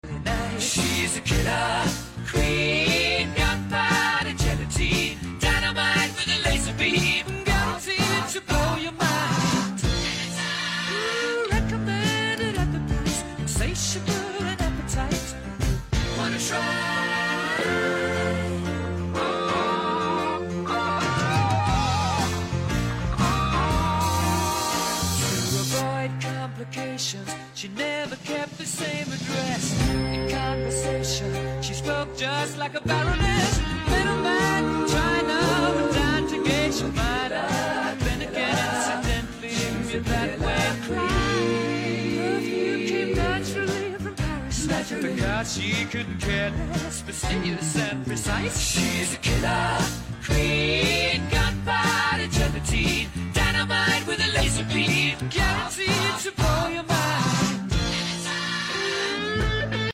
vocals
guitars
drums TRACKLIST 1.